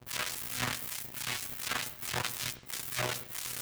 SFX_Static_Electricity_Short_04.wav